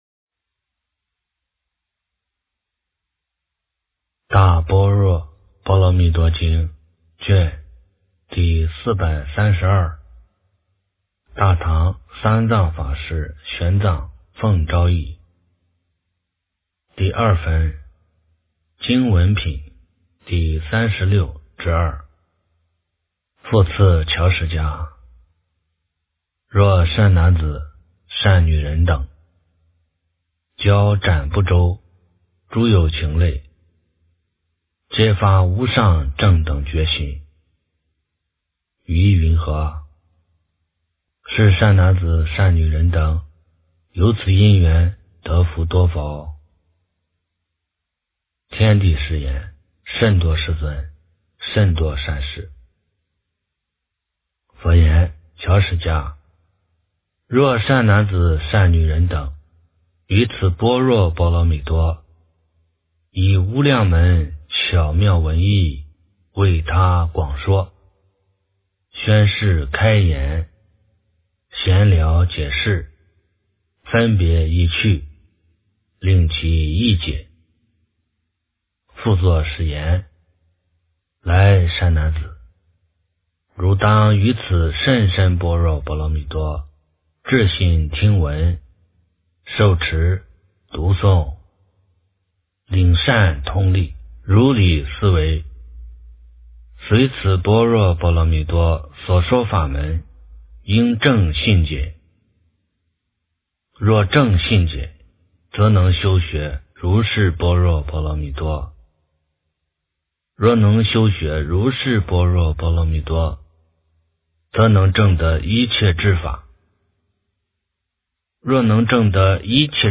大般若波罗蜜多经第432卷 - 诵经 - 云佛论坛